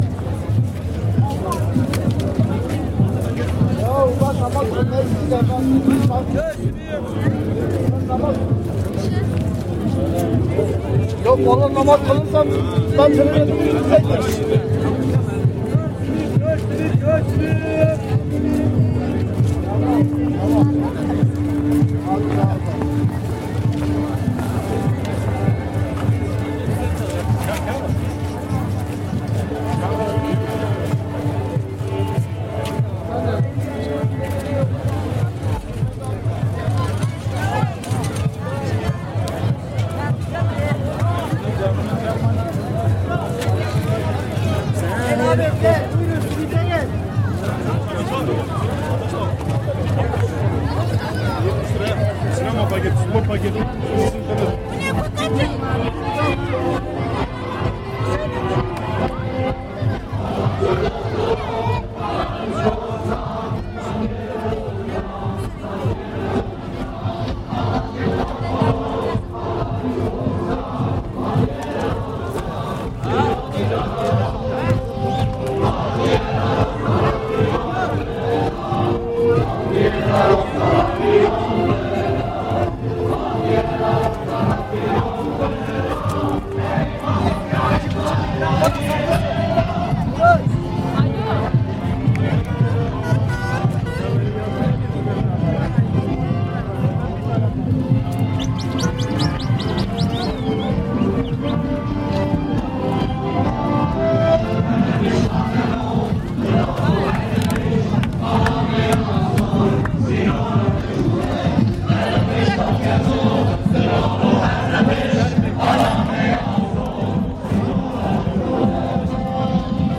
Kurdish Newroz celebrations